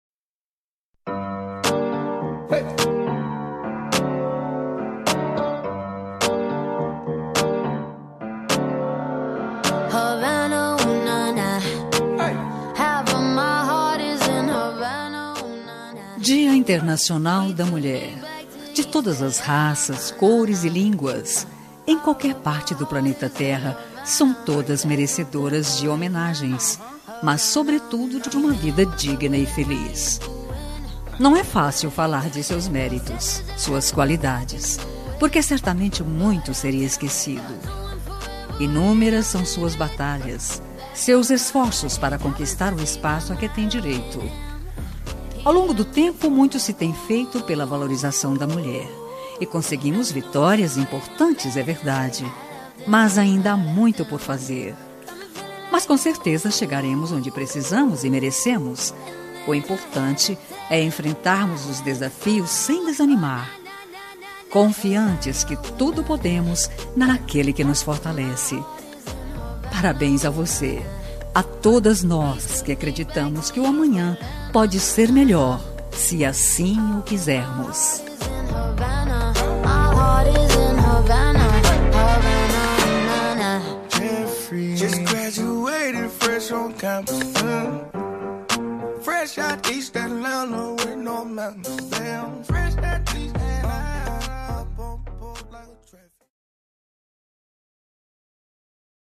Dia das Mulheres Neutra – Voz Feminina – Cód: 5288